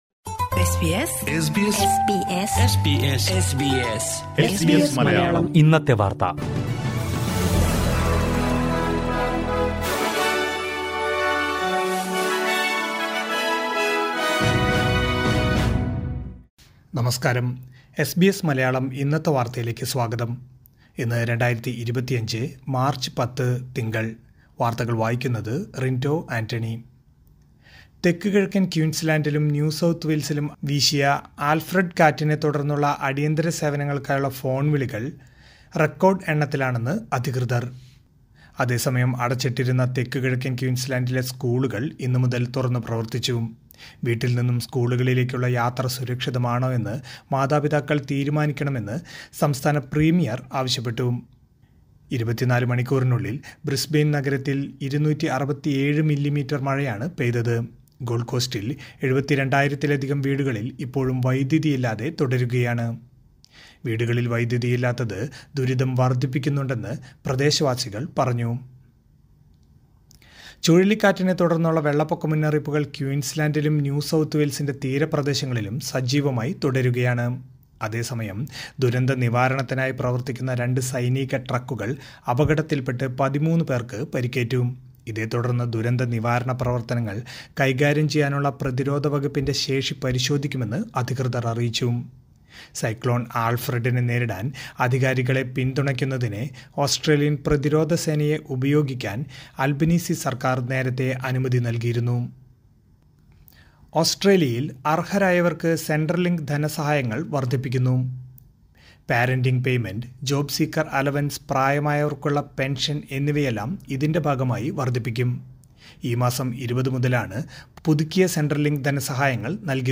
2025 മാര്‍ച്ച് പത്തിലെ ഓസ്‌ട്രേലിയയിലെ ഏറ്റവും പ്രധാന വാര്‍ത്തകള്‍ കേള്‍ക്കാം